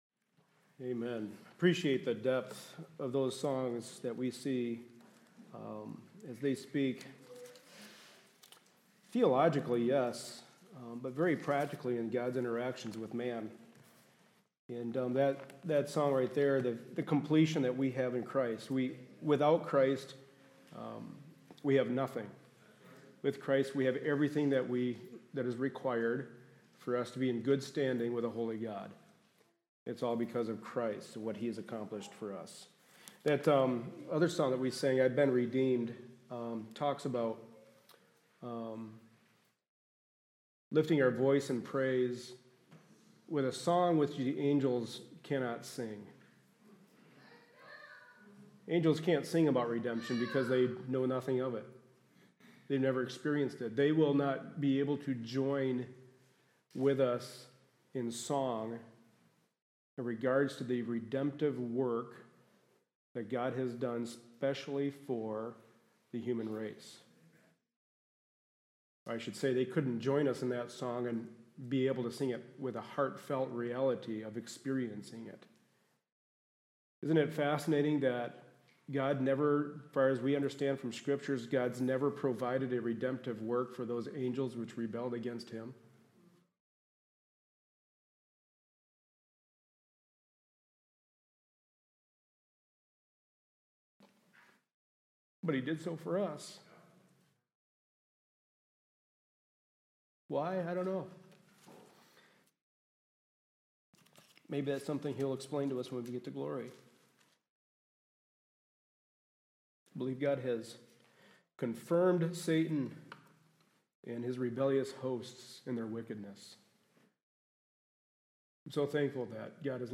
1 Timothy 3:1-7 Service Type: Sunday Morning Service A study in the pastoral epistles.